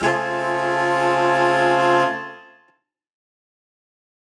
UC_HornSwellAlt_Bmin6maj7.wav